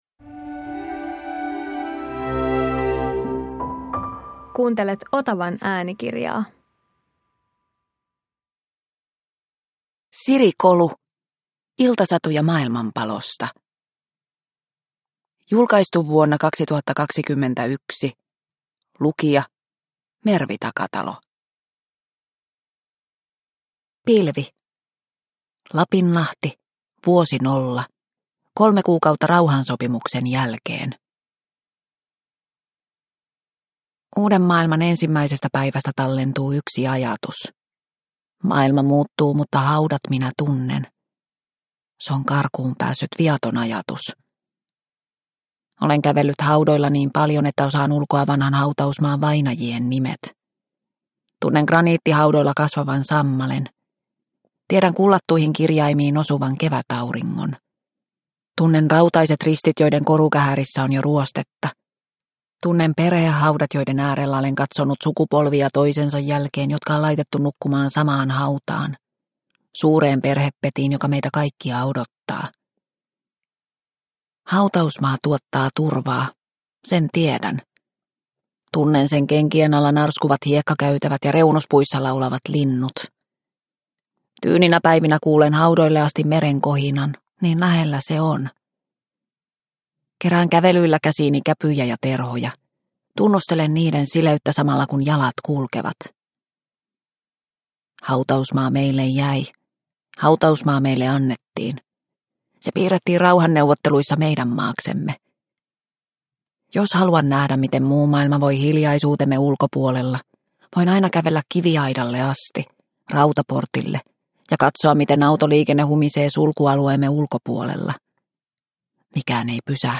Iltasatuja maailmanpalosta – Ljudbok – Laddas ner